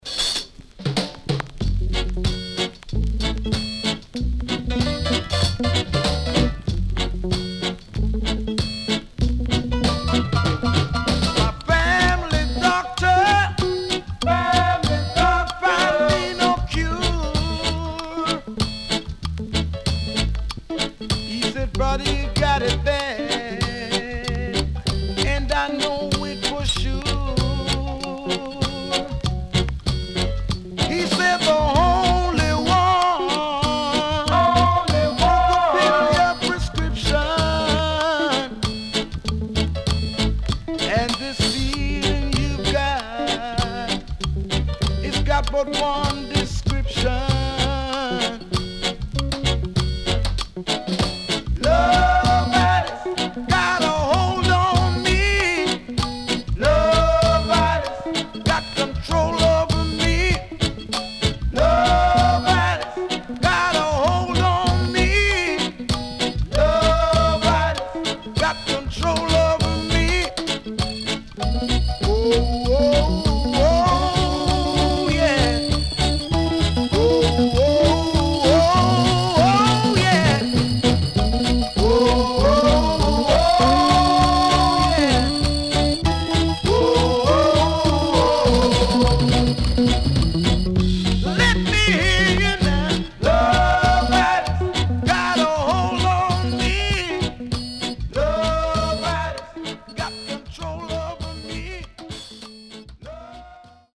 HOME > REGGAE / ROOTS
Nice Vocal.W-Side Good
SIDE A:少しチリノイズ入ります。